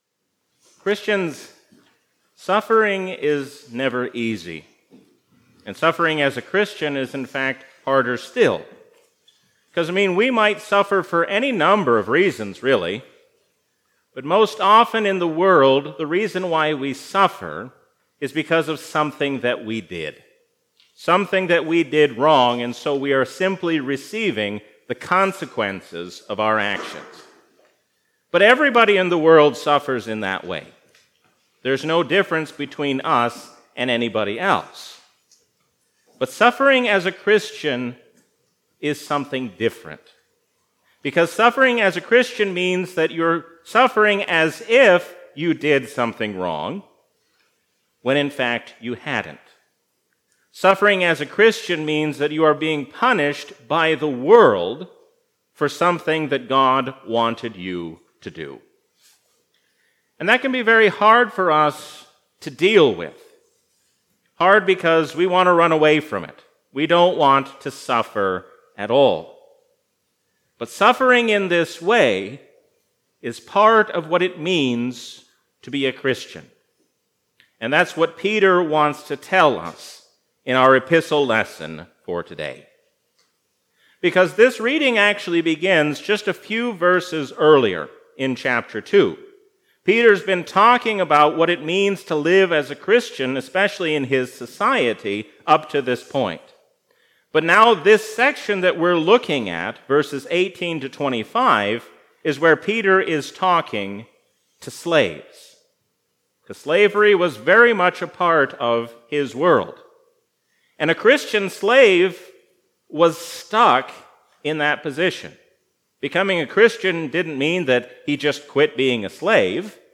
A sermon from the season "Easter 2022."